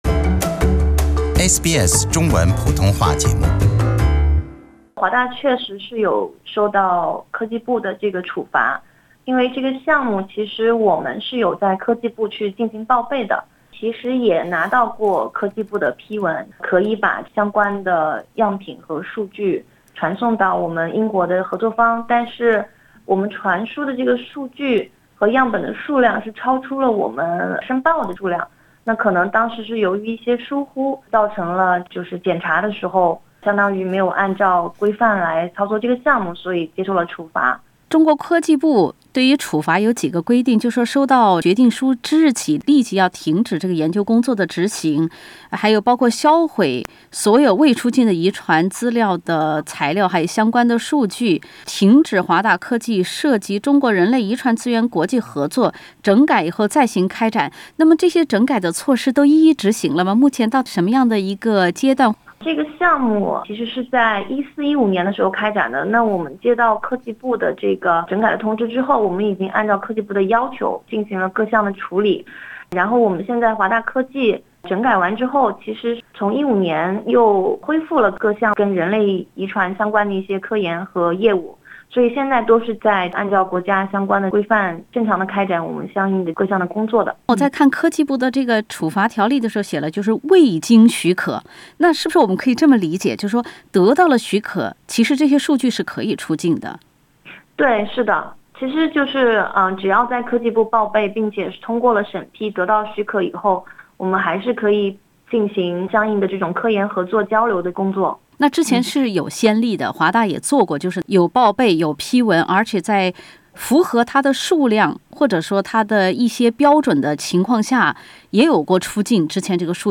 SBS SBS 普通话电台 View Podcast Series Follow and Subscribe Apple Podcasts YouTube Spotify Download